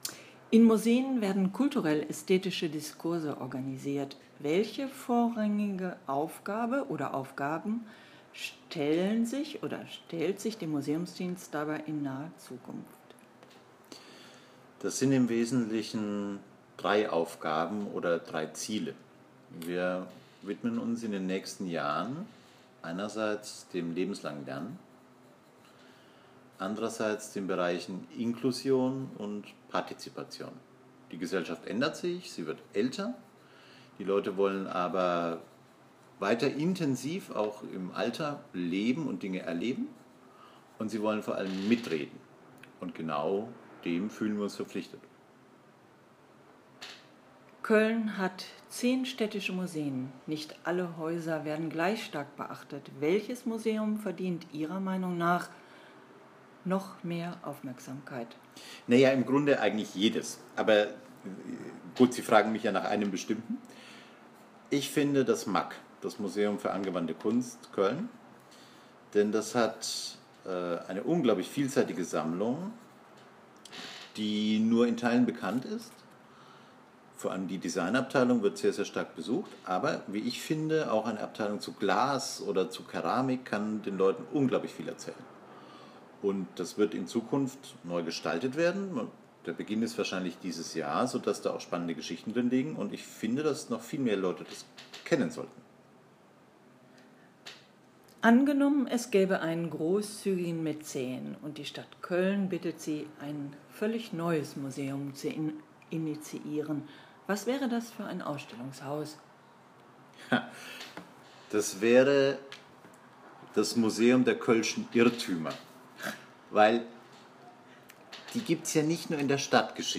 Wir reden mit Macherinnen und Machern in den Kölner Museen – über Kunst, ihre Vermittlung und die persönliche Perspektive auf das Geschehen.